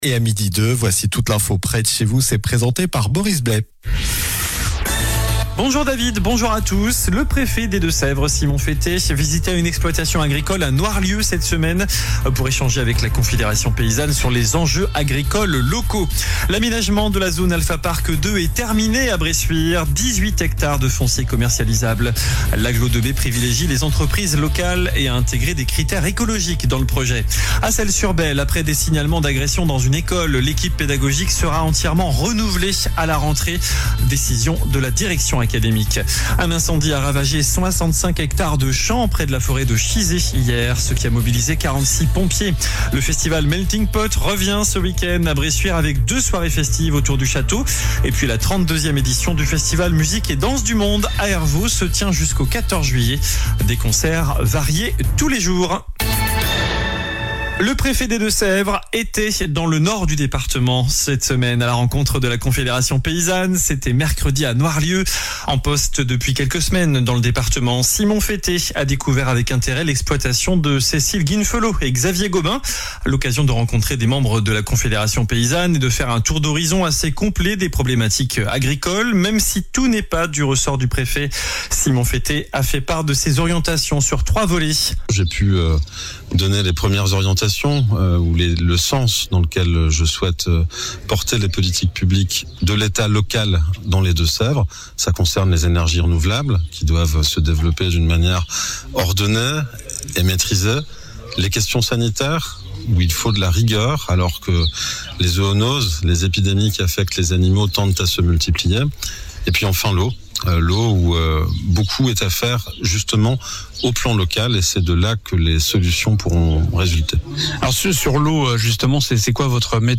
Journal du vendredi 11 juillet (midi)